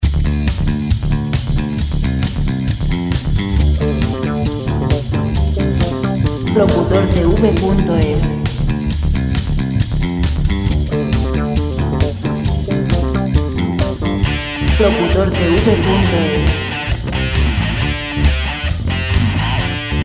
Música Rock libre de derechos de autor
Ejemplos de canciones rock libres de derechos de autor.
135 BPM